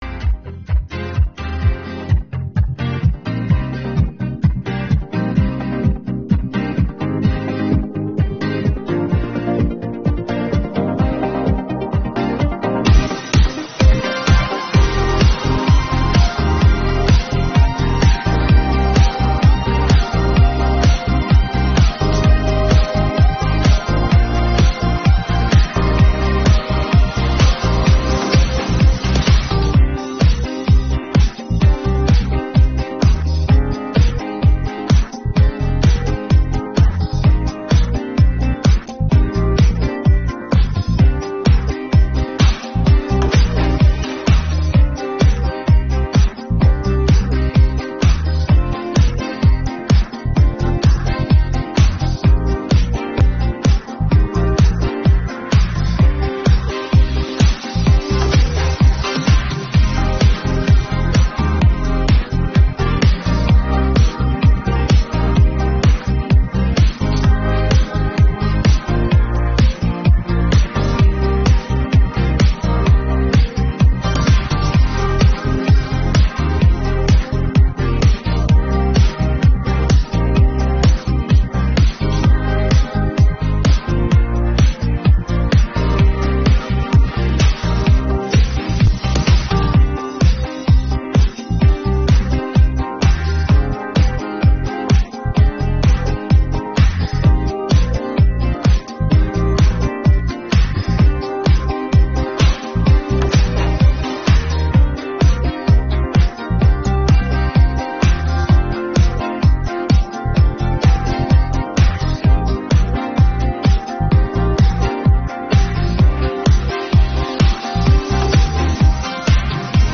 Мінусовка та караоке для співу онлайн – насолоджуйтесь!